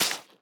Minecraft Version Minecraft Version latest Latest Release | Latest Snapshot latest / assets / minecraft / sounds / block / sponge / wet_sponge / step3.ogg Compare With Compare With Latest Release | Latest Snapshot